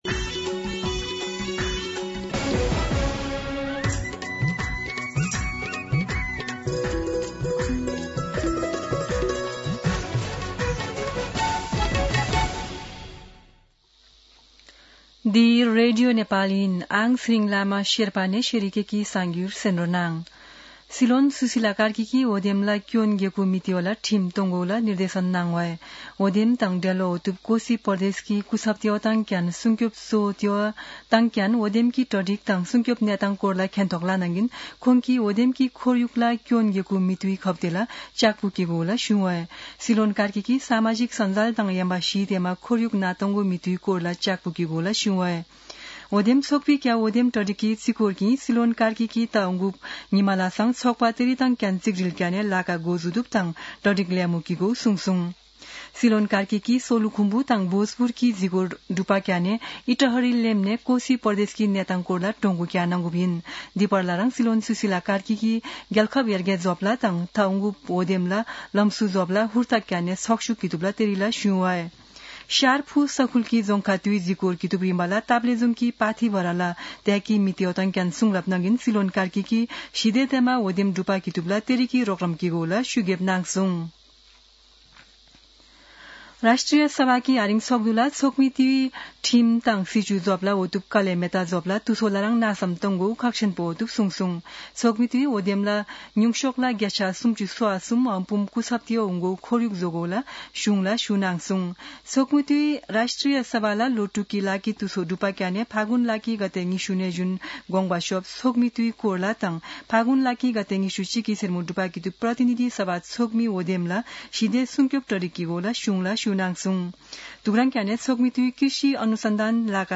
शेर्पा भाषाको समाचार : १ फागुन , २०८२
Sherpa-News-11-1.mp3